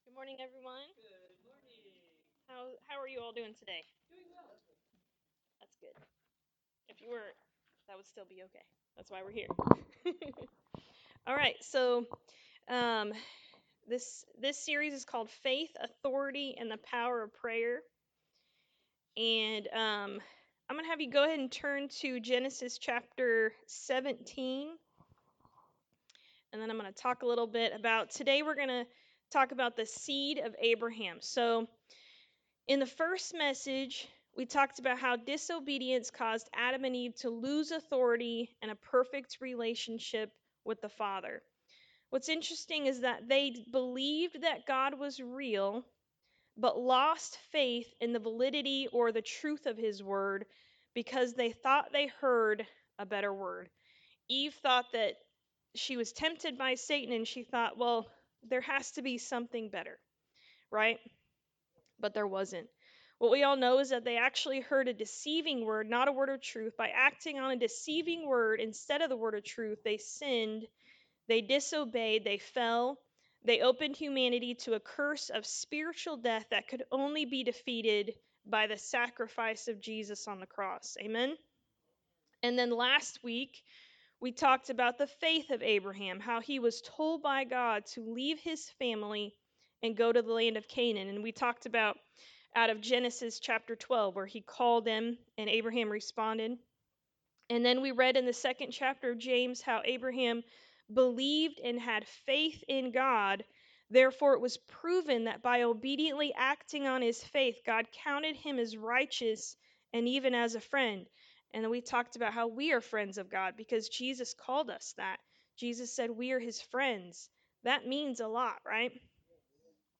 Service Type: Sunday Morning Service
Sunday-Sermon-for-August-3-2025.mp3